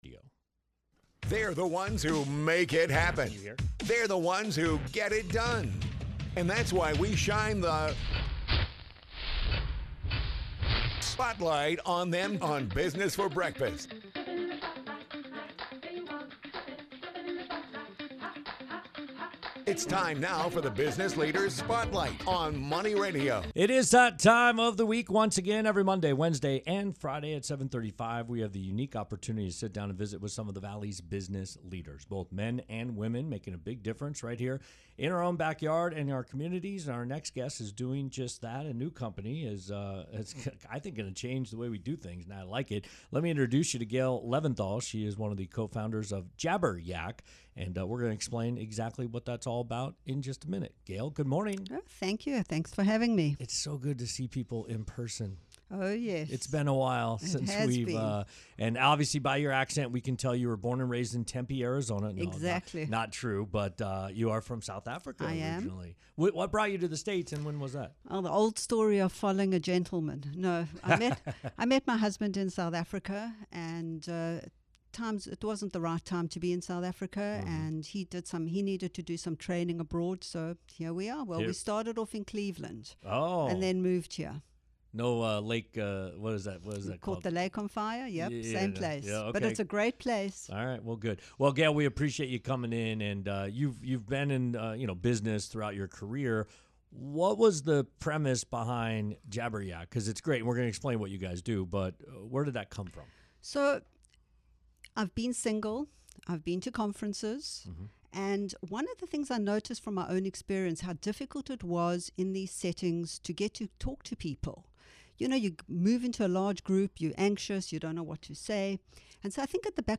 Interview on Money Radio